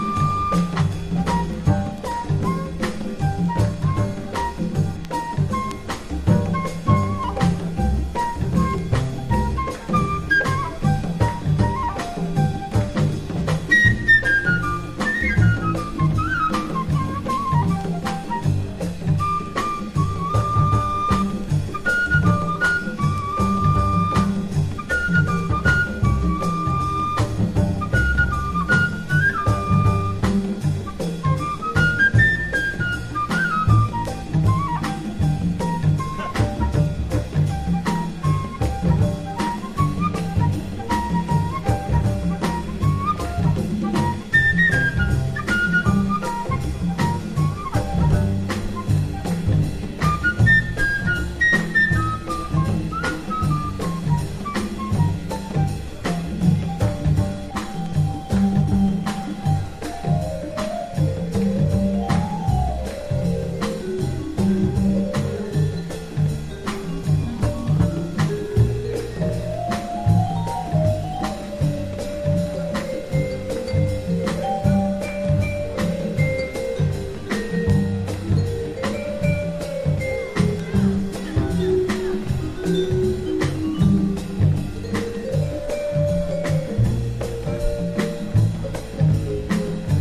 うねるリズム隊の上を優雅にフルートが舞うA1やコンガによるラテンリズムを取り入れたA2等、エスノな空気が全体を覆う1枚。
ハードバップ